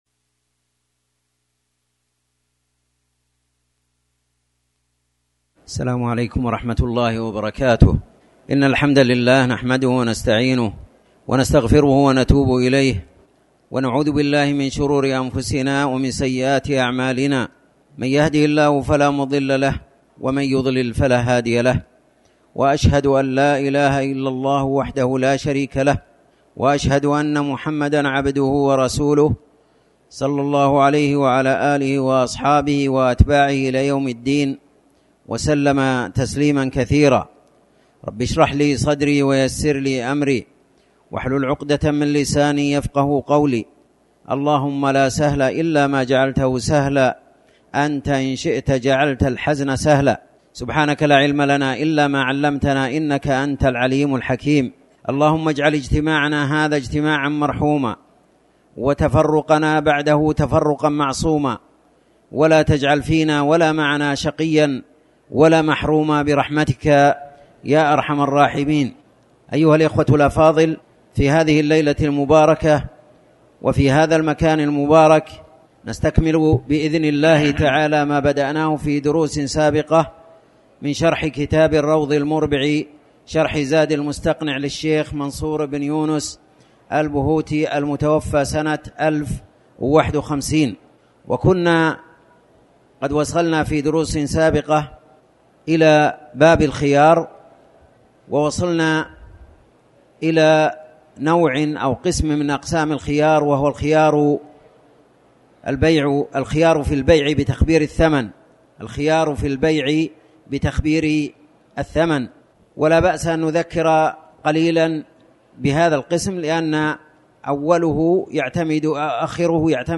تاريخ النشر ١٤ صفر ١٤٤٠ هـ المكان: المسجد الحرام الشيخ